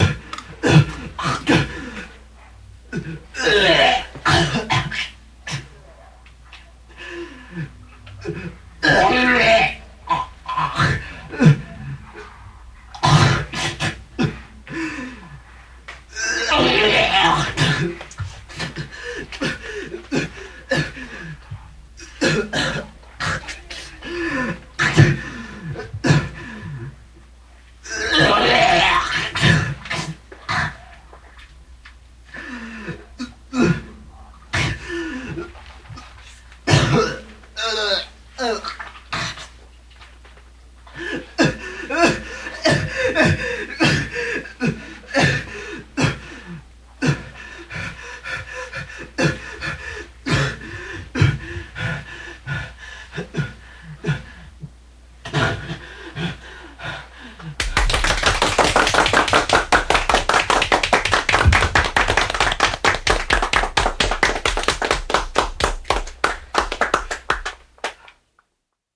• Genre: Japanese Noise